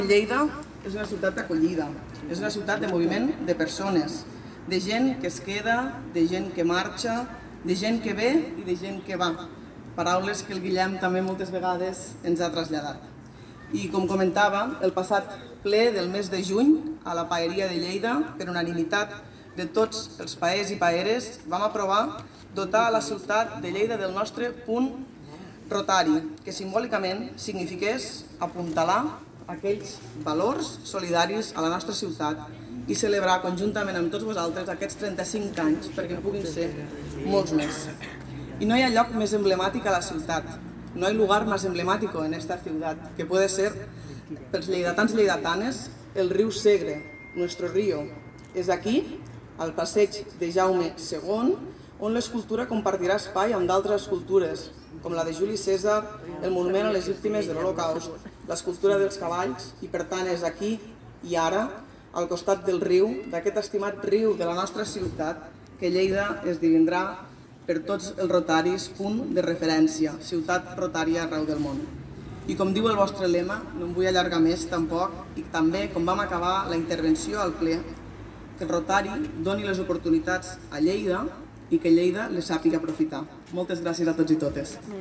tall-de-veu-jordina-freixanet